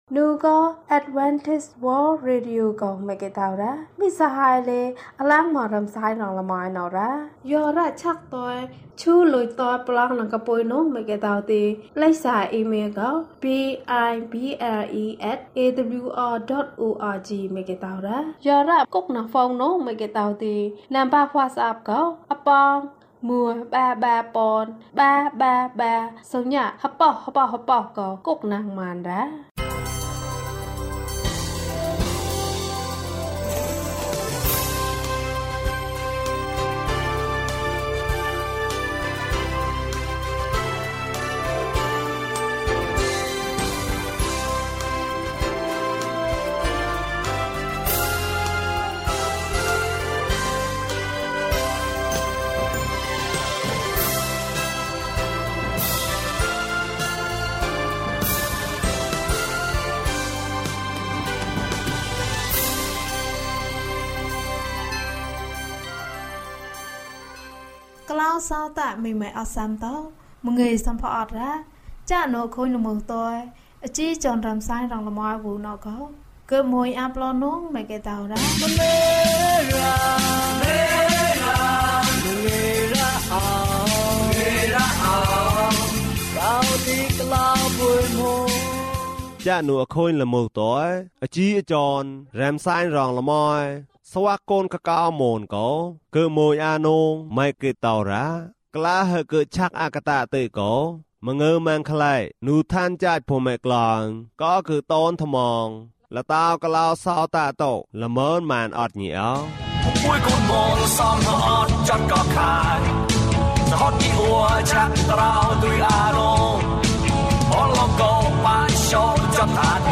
သမ္မာကျမ်းစာ။၀၁ ကျန်းမာခြင်းအကြောင်းအရာ။ ဓမ္မသီချင်း။ တရားဒေသနာ။